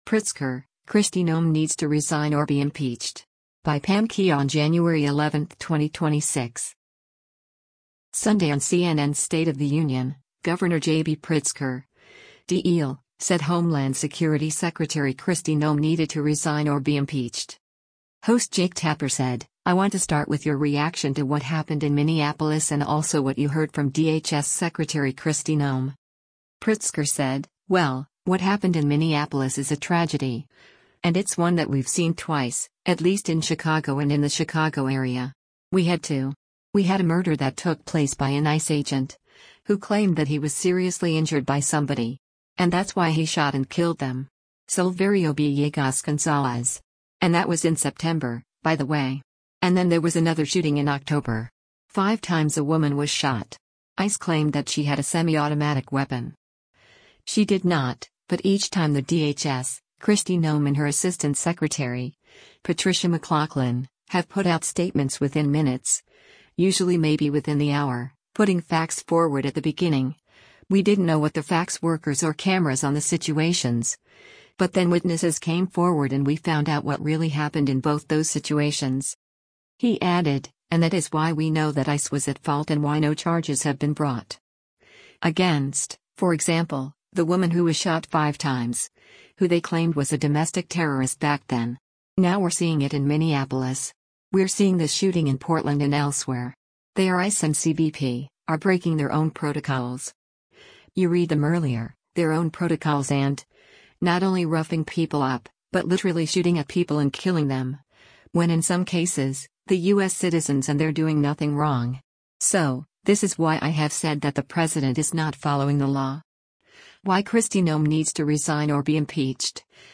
Sunday on CNN’s “State of the Union,” Gov. JB Pritzker (D-IL) said Homeland Security Secretary Kristi Noem needed “to resign or be impeached.”